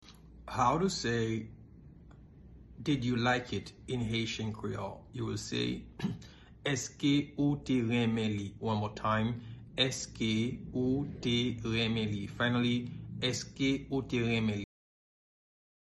Listen to and watch “Èske ou te renmen li?” pronunciation in Haitian Creole by a native Haitian  in the video below:
Did-you-like-it-in-Haitian-Creole-Eske-ou-te-renmen-li-pronunciation-by-a-Haitian-teacher.mp3